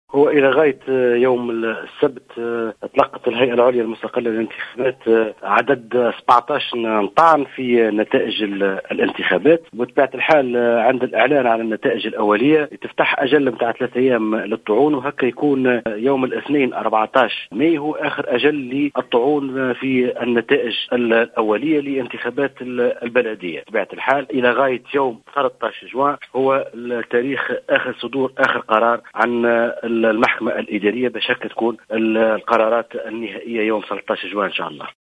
أكد رئيس الهيئة العليا المستقلة للإنتخابات محمد التليلي منصري في تصريح للجوهرة "اف ام" اليوم الأحد أن الهيئة إلى غاية يوم أمس السبت 17 طعنا في النتائج الأولية للإنتخابات البلدية التي جرت يوم الأحد 6 ماي 2018.